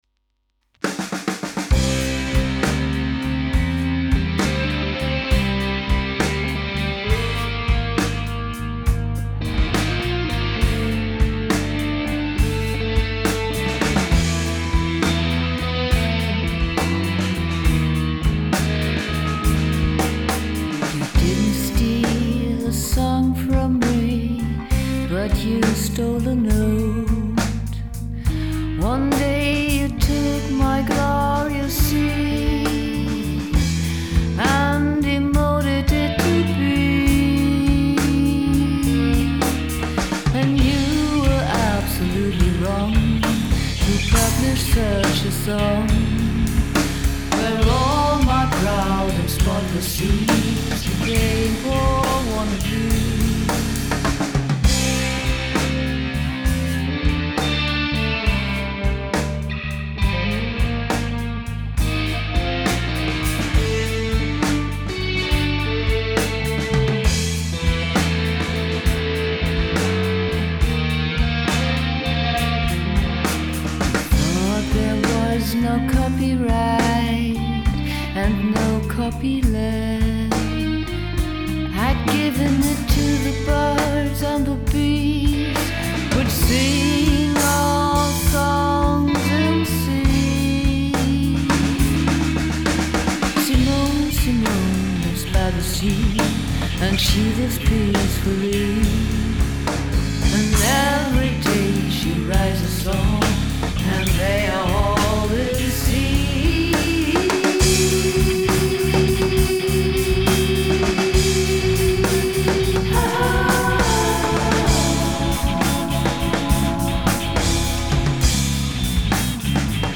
Hier sind Aufnahmen an denen ich mit meinem Bass beteiligt bin.
Gesang/Drums. Diesmal habe ich auch die Gitarre gespielt